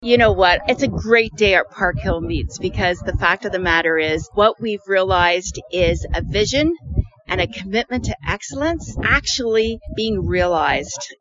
Minister Thompson had the chance to share her thoughts during the groundbreaking and showed her complete support for the project.